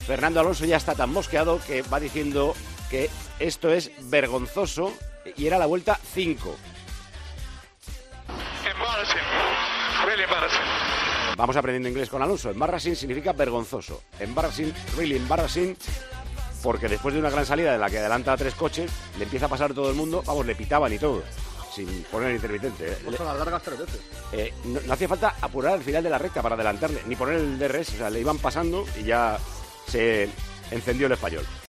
El piloto asturiano se quejó a su equipo por la radio por los problemas en el motor que hacían que todos los pilotos le pasaran.